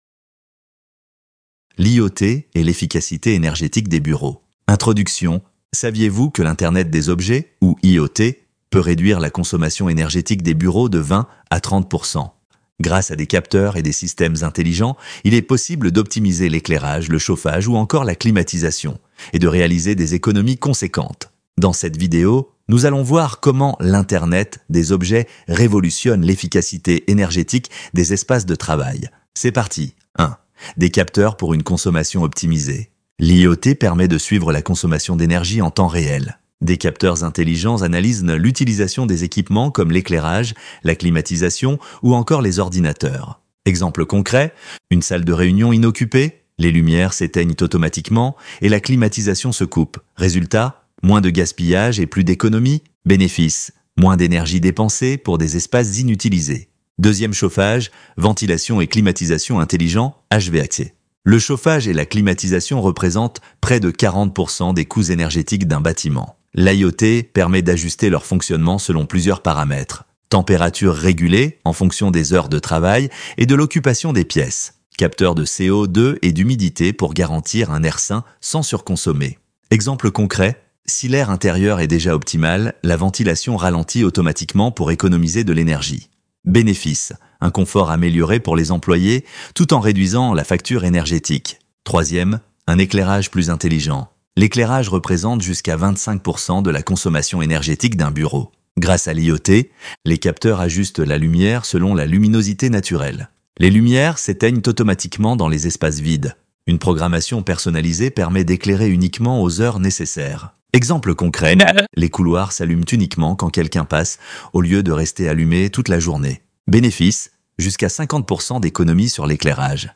Liot-voix-off.mp3